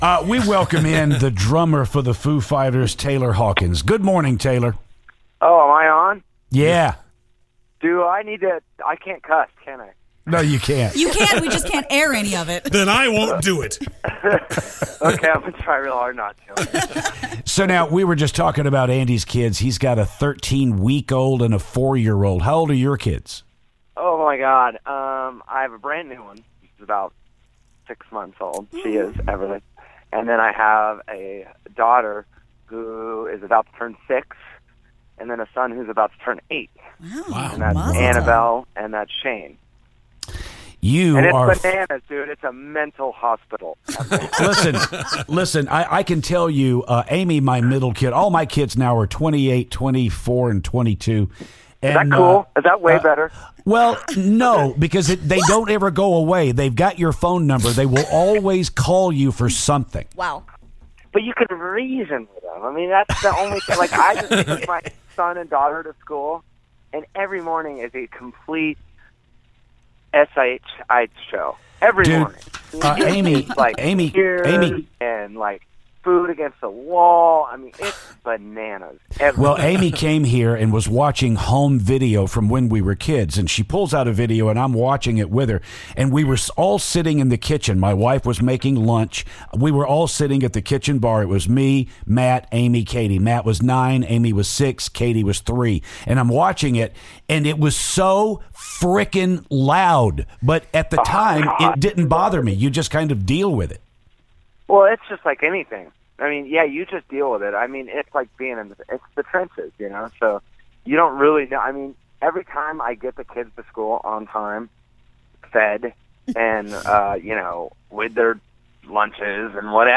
Foo Fighters drummer Taylor Hawkins calls the show!